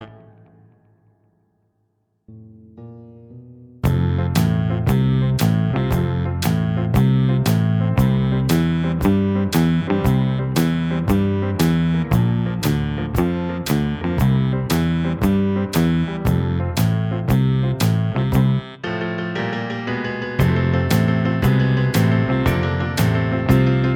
Minus Guitars Pop (1960s) 2:07 Buy £1.50